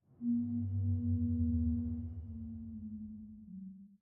bass_whale2.ogg